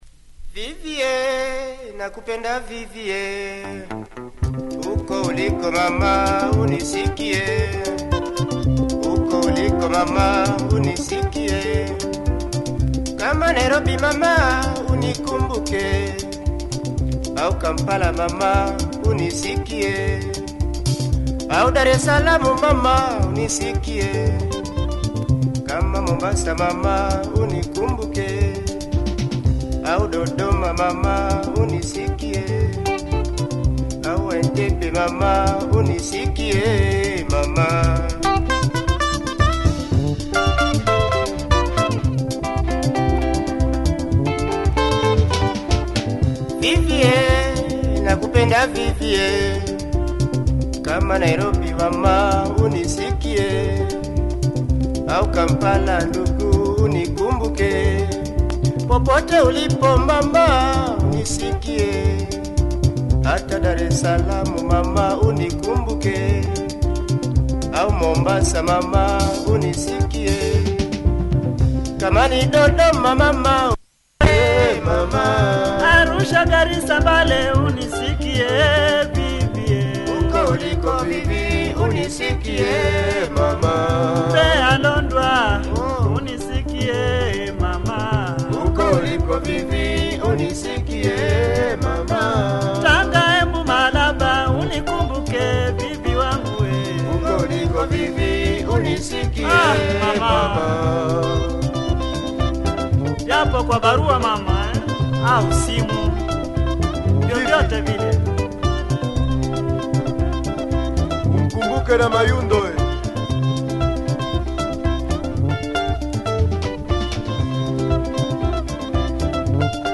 Good stick drumming and great guitar